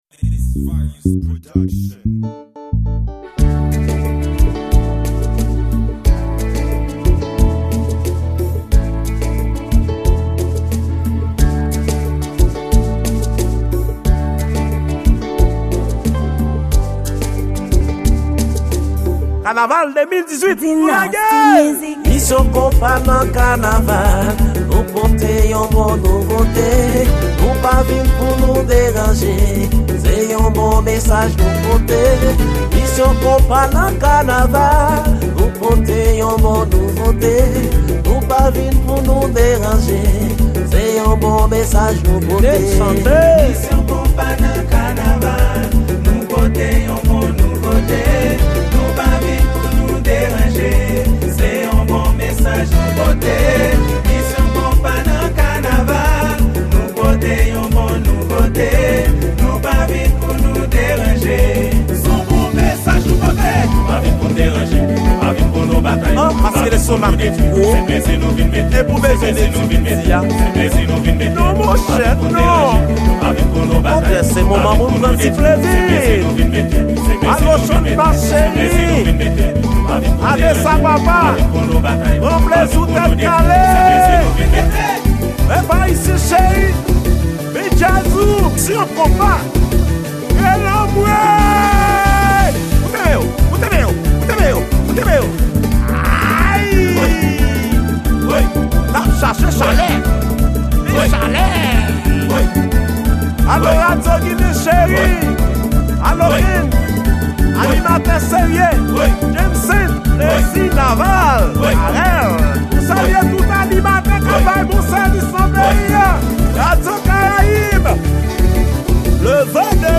Genre: Kanaval